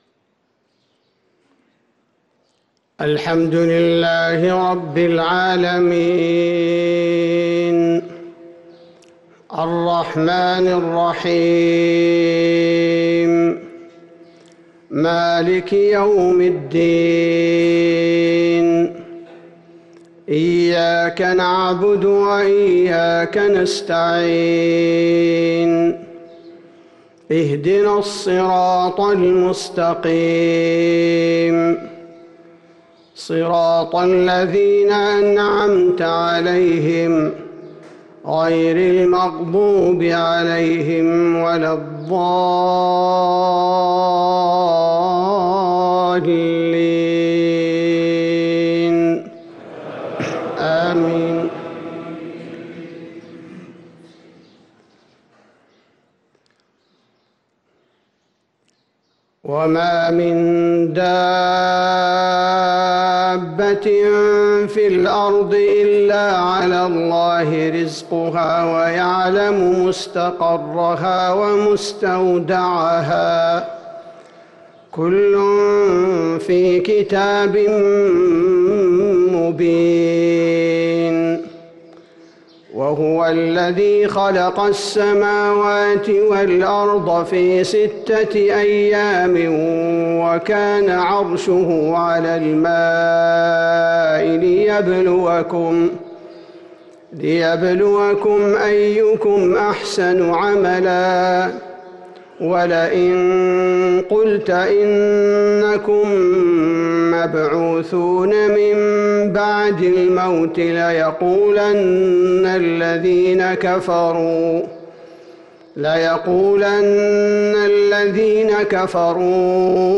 صلاة الفجر للقارئ عبدالباري الثبيتي 4 جمادي الآخر 1445 هـ
تِلَاوَات الْحَرَمَيْن .